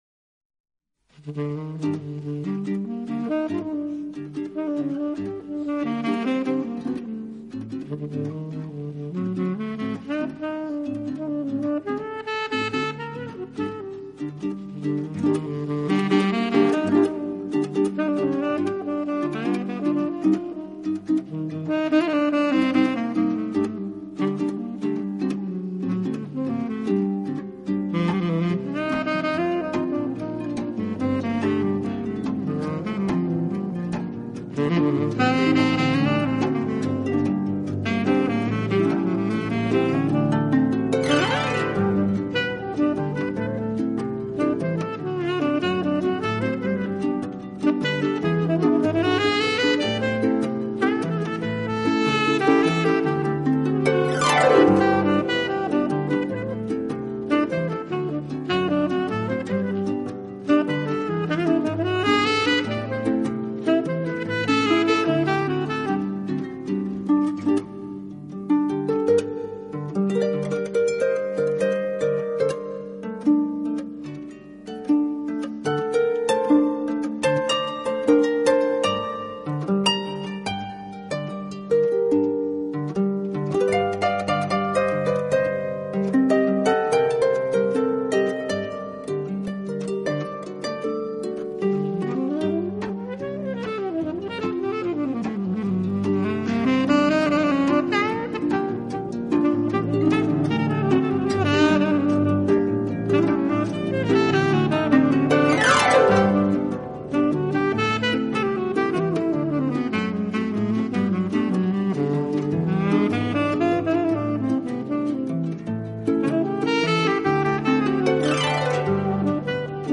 通透的萨克斯、清脆的竖琴，音色粒粒传神！